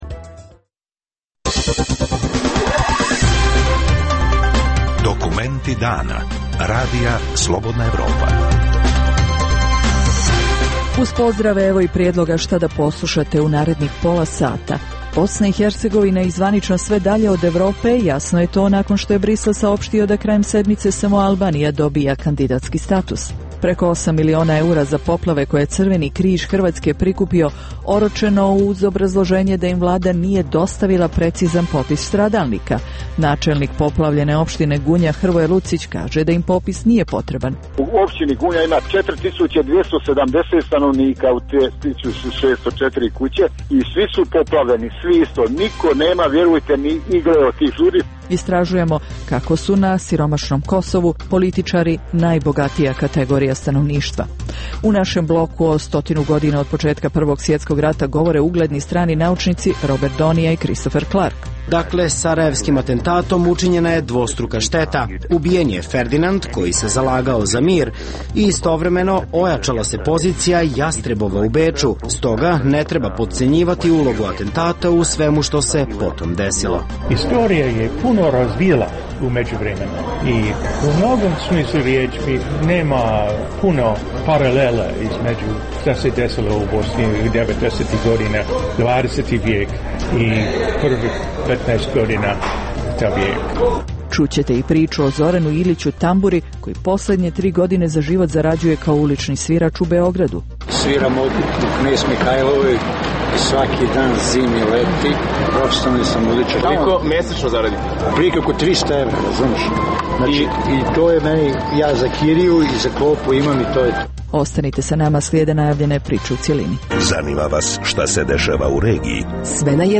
Dnevna emisija u kojoj dublje istražujemo aktuelne događaje koji nisu u prvom planu kroz intervjue, analize, komentare i reportaže. Žučna rasprava u crnogorskom parlamentu uz prisustvo premjera Đukanovića.